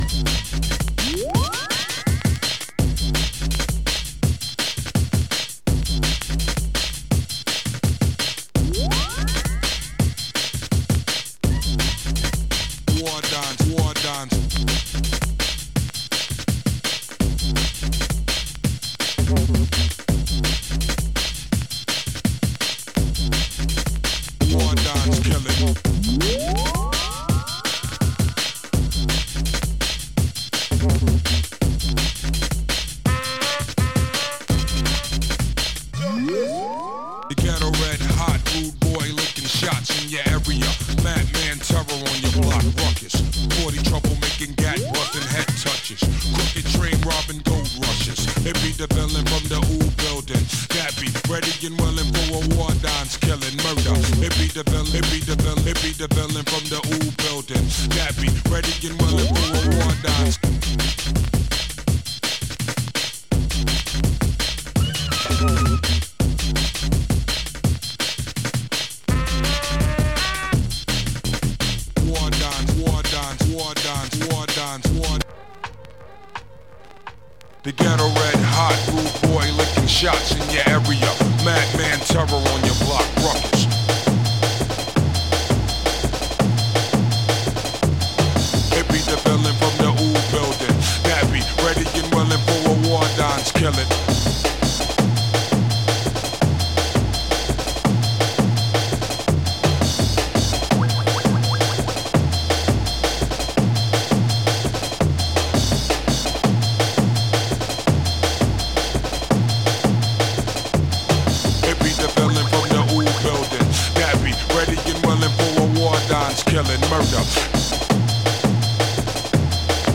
Original Dubplate